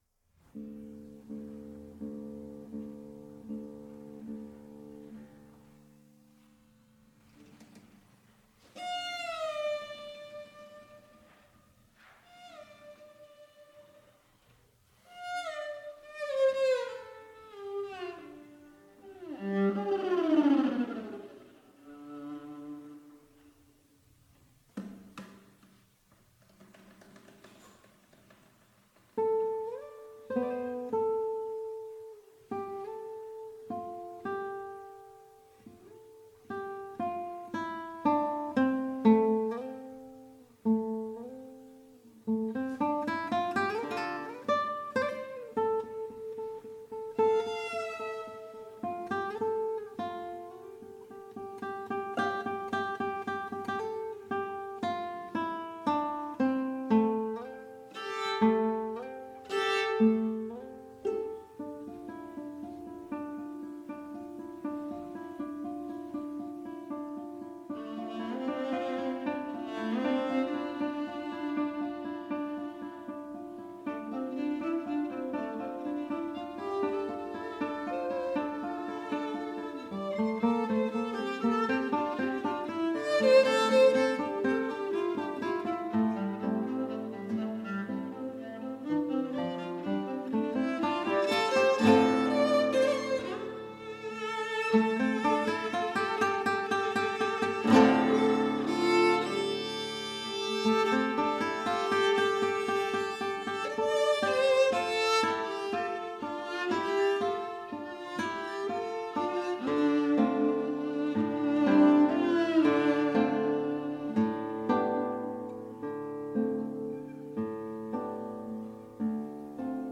for Viola and Guitar (2018)